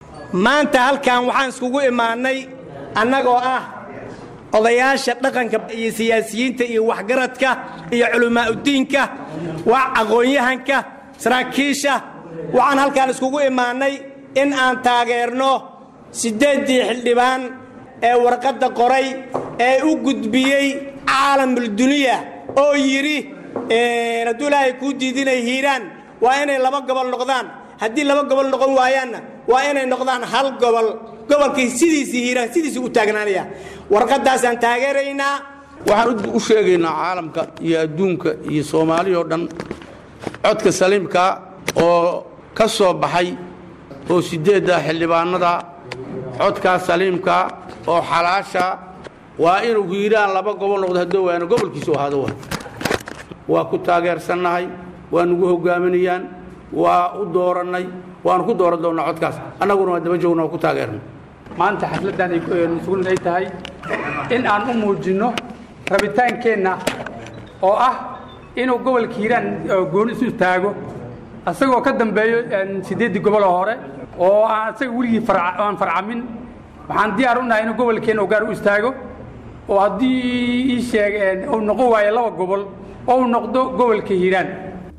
Sabti, Juun 20, 2015 (HOL) — Kulan ballaanran oo lagu Taageerayay talo ay qaar ka mid ah xildhibaanndaa reer Hiiraan soo jeediyeen oo ahayd in Gobolka Hiiraan laba gobol loo qaybiyo ama sidiisa looga dhigo Maamul-goboleed ayaa shalay lagu qabtay Muqdisho.
DHAGEYSO: HADALLADII AY WAXGARADKU KA JEEDIYEEN KULANKA: